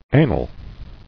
[a·nal]